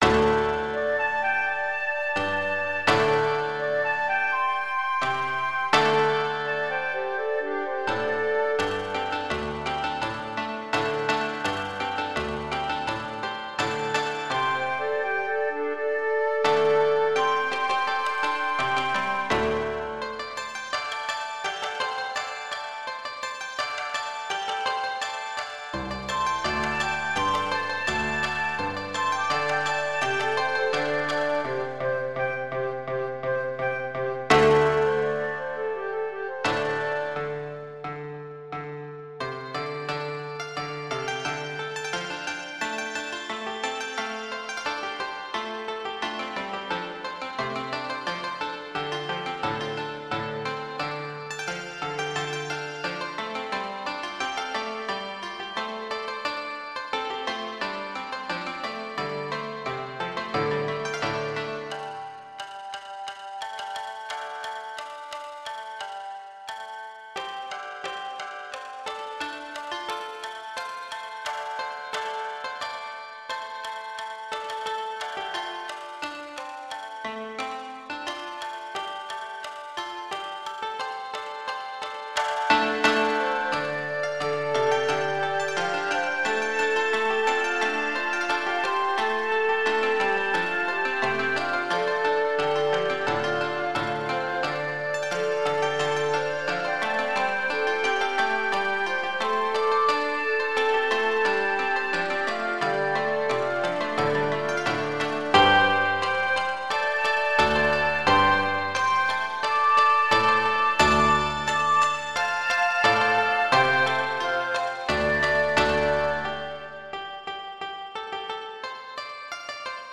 箏1
箏2
十七絃
三絃
尺八1（d）
尺八2（d）
理想郷は憧れの世界にあるからいいのであって、最後に玉の枝のテー